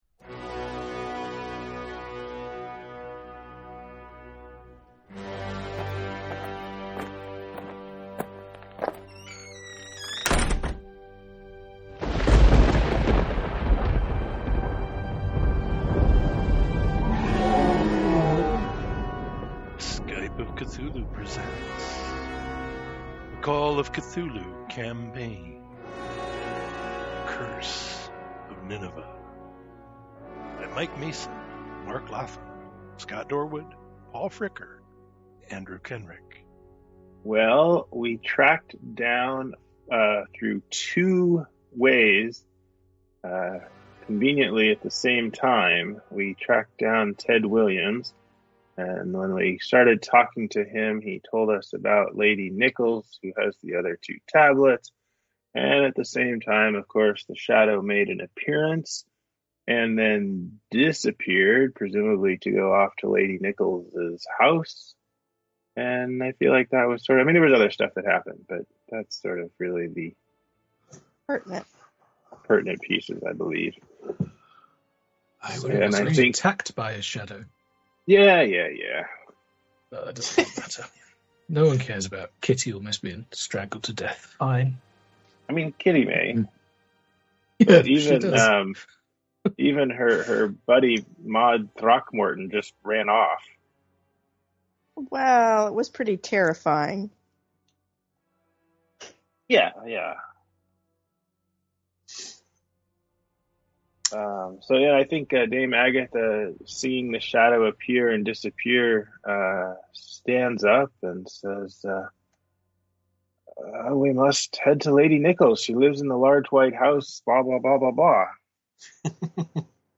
Call of Cthulhu, and perhaps other systems on occasion, played via Skype.